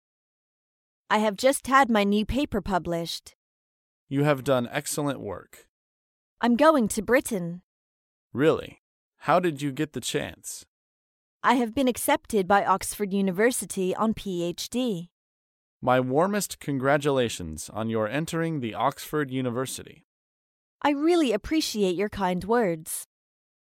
在线英语听力室高频英语口语对话 第131期:祝贺考入名牌学府的听力文件下载,《高频英语口语对话》栏目包含了日常生活中经常使用的英语情景对话，是学习英语口语，能够帮助英语爱好者在听英语对话的过程中，积累英语口语习语知识，提高英语听说水平，并通过栏目中的中英文字幕和音频MP3文件，提高英语语感。